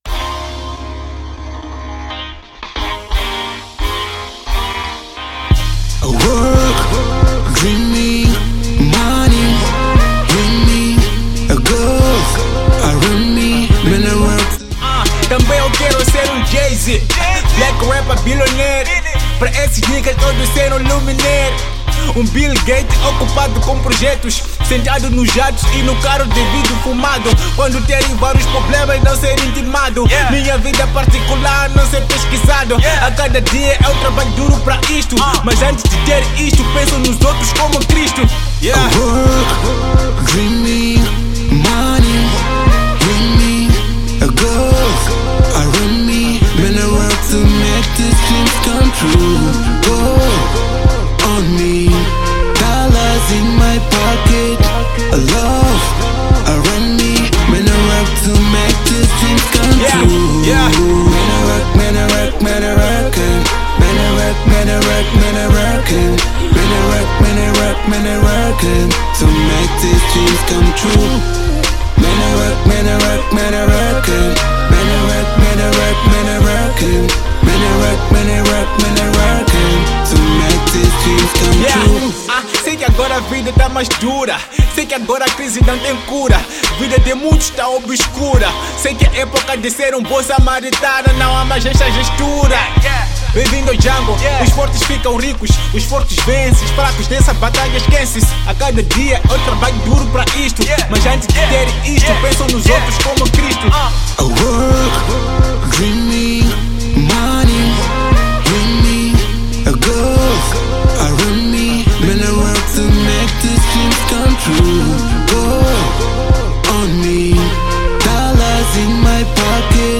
Rap
Hip Hop Moz 2023 Download